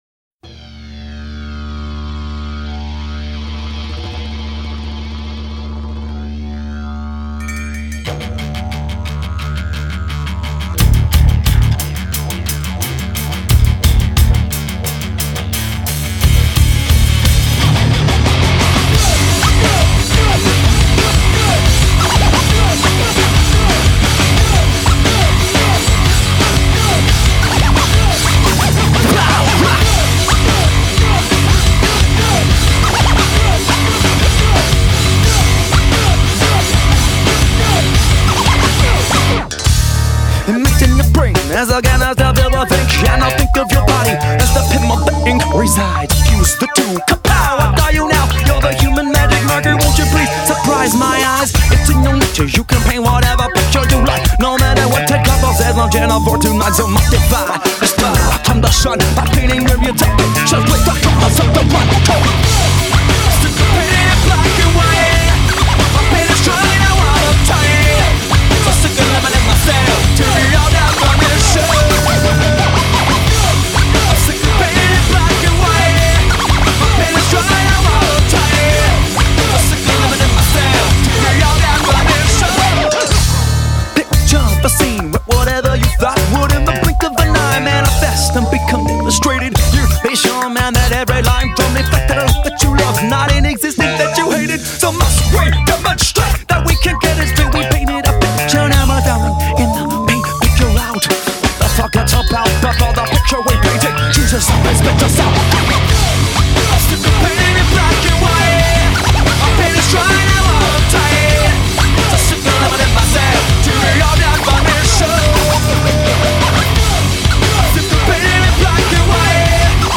Tags: alternative rock random sounds funny sounds